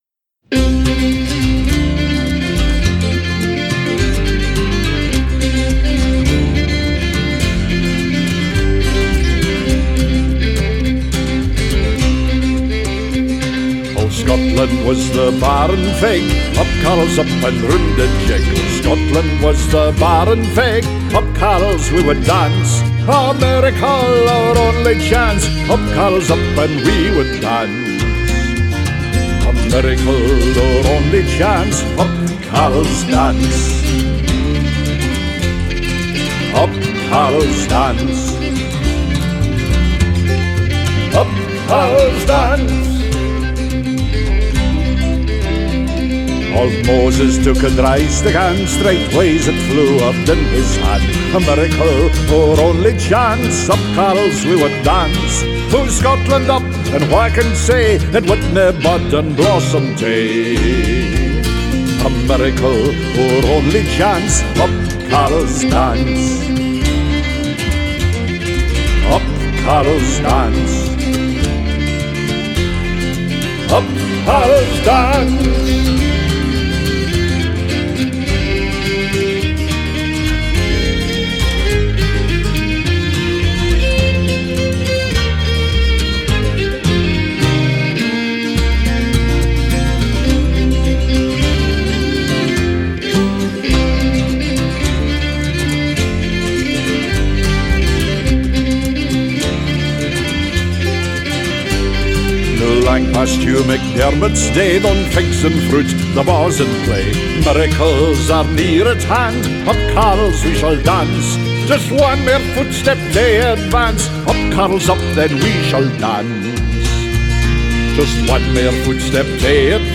wee song
Scottish Traditional Songwriter & Singer